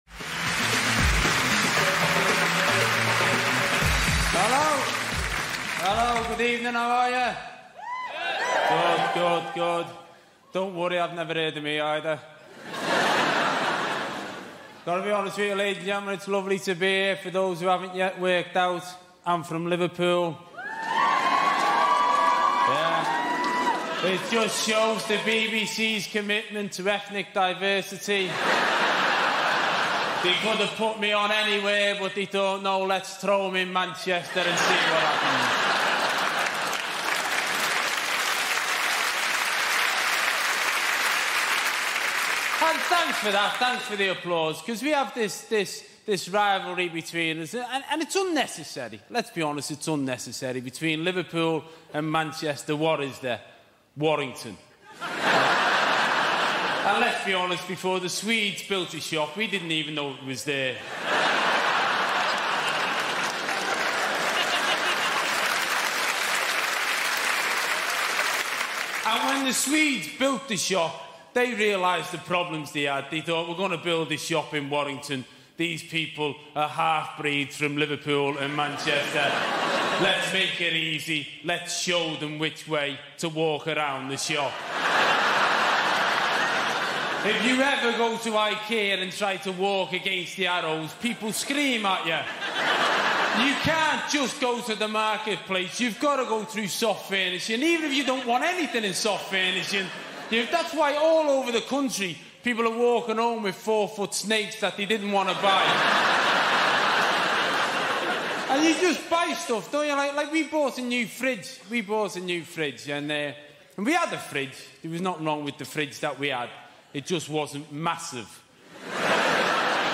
Before the first laugh even lands, you should know this: every episode of The Comedy Room places all advertisements right at the beginning, so once the show truly starts, nothing interrupts the flow, the rhythm, or the feeling.